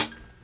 Closed Hats
07_Hats_05_SP.wav